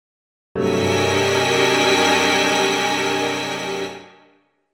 Having made the journey from the (relatively) straightforward major triad to the tonal saturation of the twelve-note chord.
12note.mp3